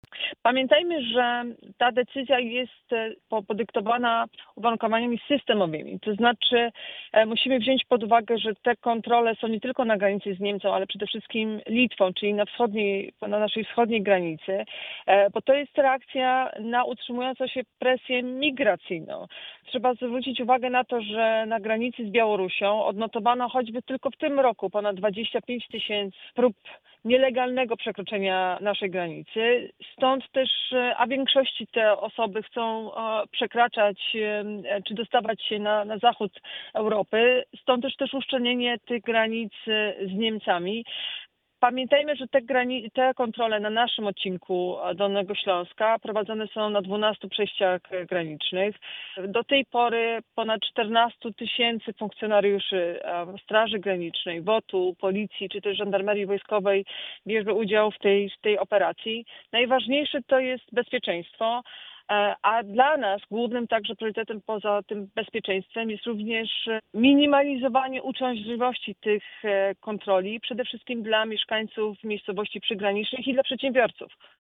Pytaliśmy również o przedłużenie tymczasowych kontroli granicznych z Niemcami do 4 kwietnia 2026 roku. Wyjaśniła Wojewoda: